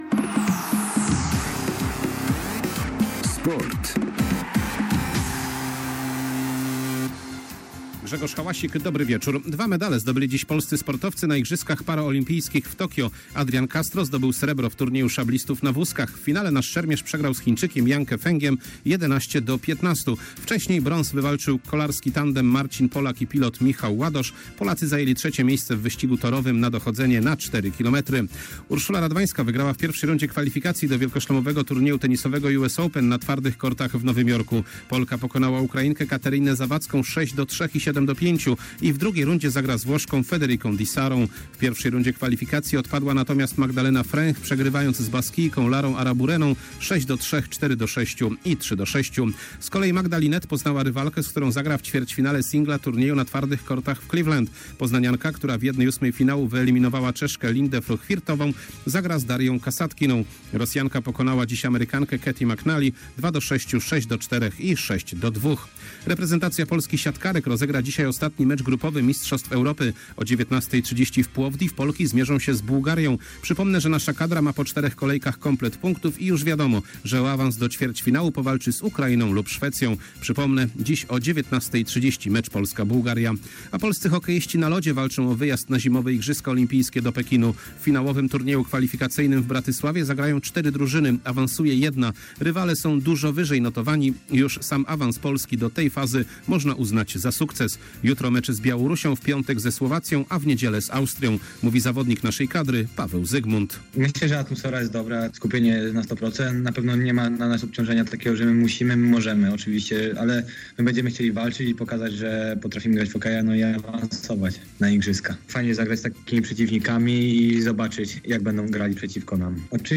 25.08.2021 SERWIS SPORTOWY GODZ. 19:05